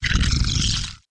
dragon_select1.wav